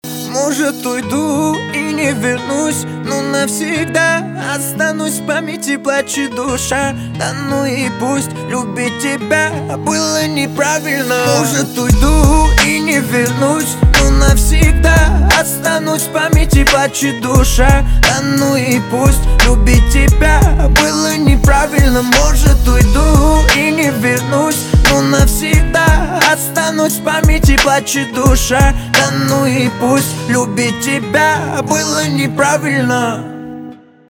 русский рэп , битовые , басы
грустные , печальные
гитара